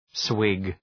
Shkrimi fonetik {swıg}